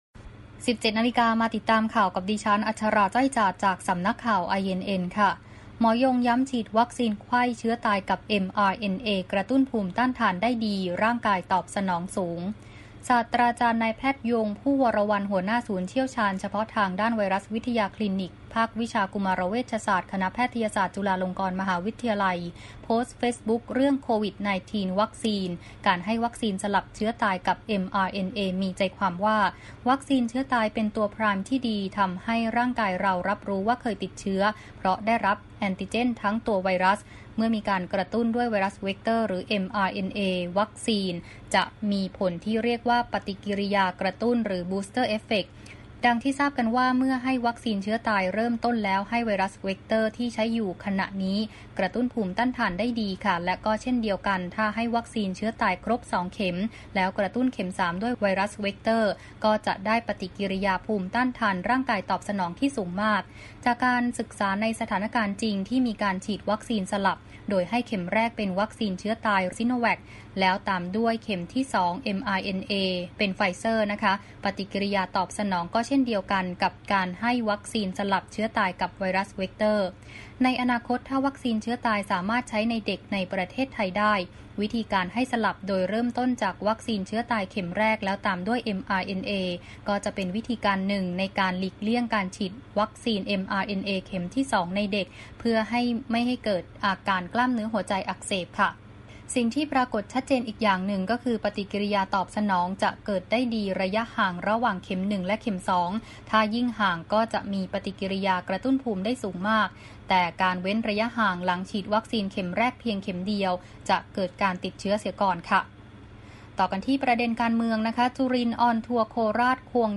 คลิปข่าวต้นชั่วโมง
ข่าวต้นชั่วโมง 17.00 น.